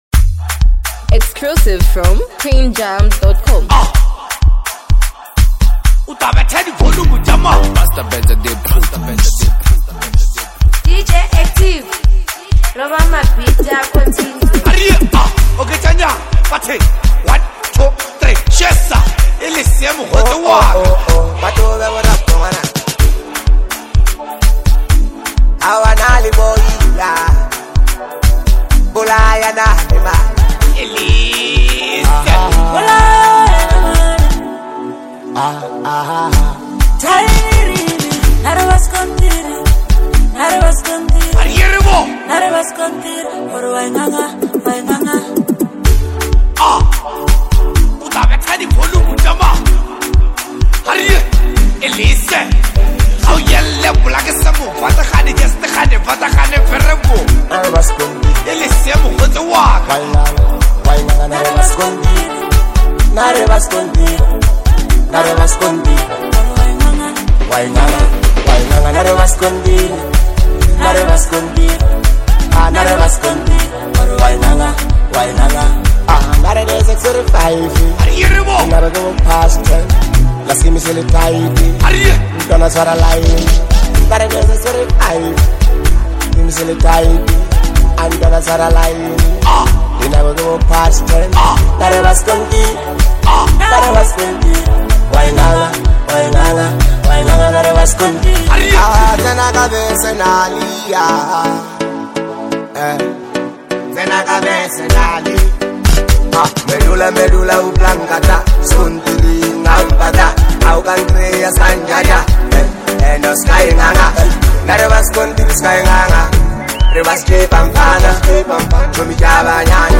energetic anthem
catchy vocal hooks that make the song easy to vibe along to
upbeat, catchy, and made for the dancefloor.